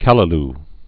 (kălə-l, kălə-l)